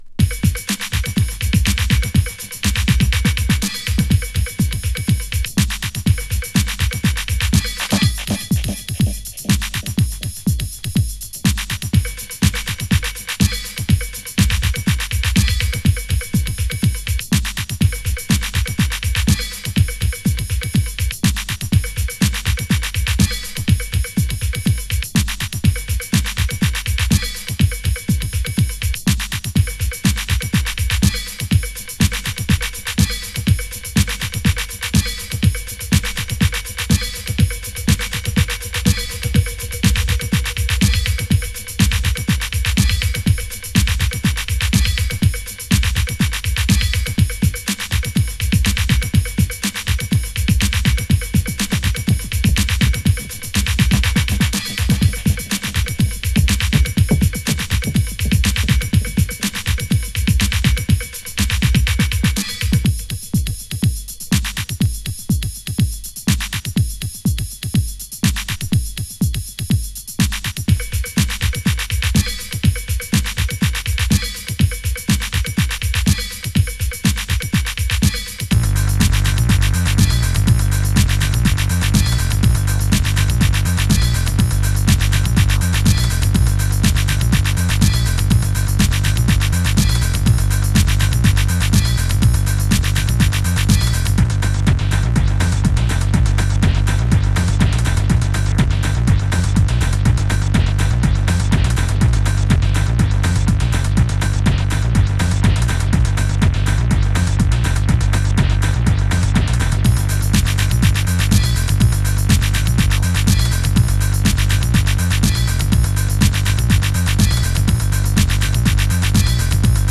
タイトルからも解るようにアシッド・テクノ全開。